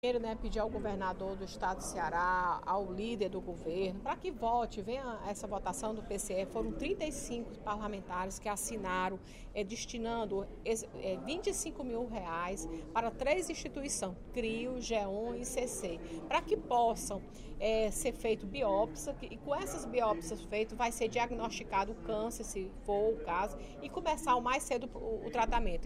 A deputada Fernanda Pessoa (PR) alertou, nesta quarta-feira (31/05), durante o primeiro expediente da sessão plenária da Assembleia Legislativa, para os direitos e as necessidades dos pacientes com câncer de mama metastático.